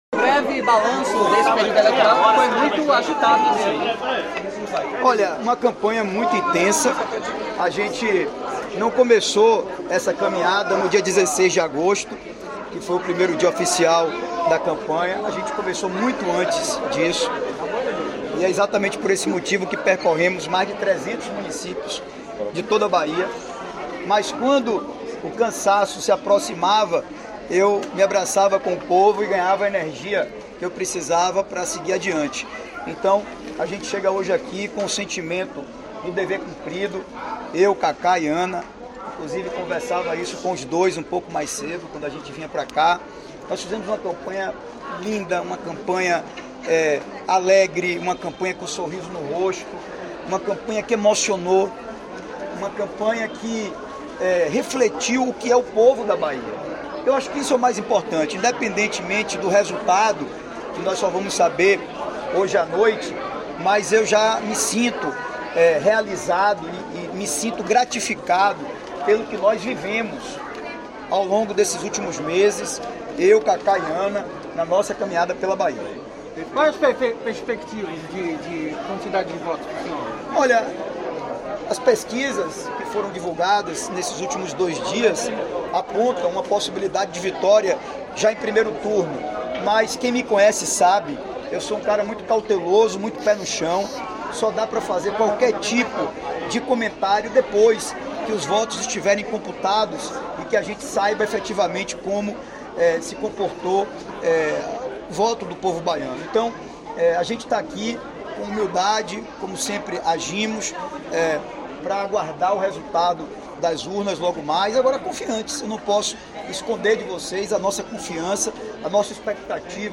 Sonora de ACM Neto logo após votar neste domingo, na Escola de Administração da UFBA